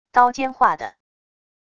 刀尖划地wav音频